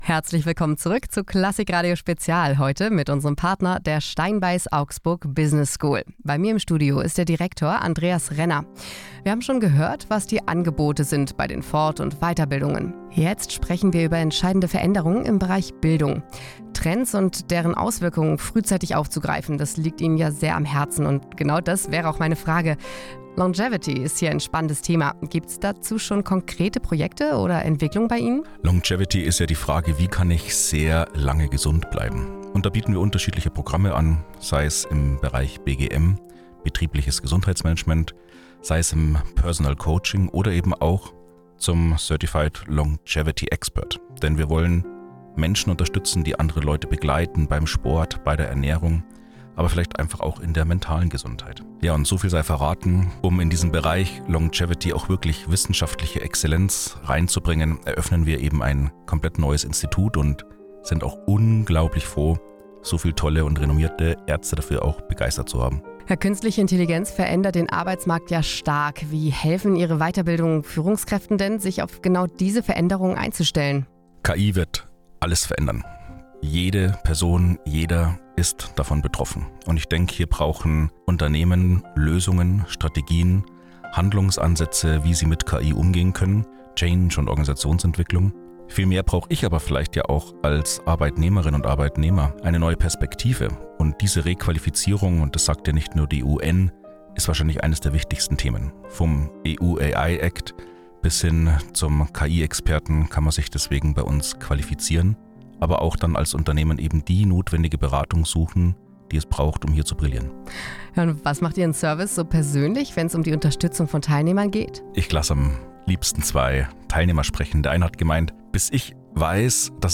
KLASSIK RADIO INTERVIEW